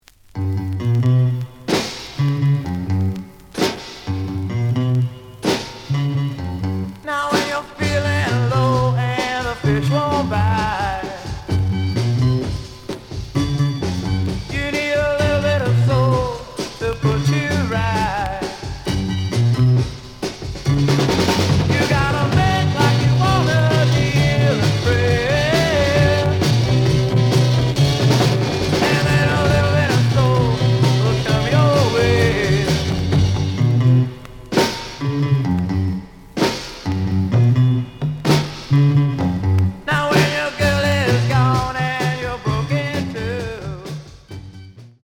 試聴は実際のレコードから録音しています。
●Format: 7 inch
●Genre: Rock / Pop
A面の録音が不安定。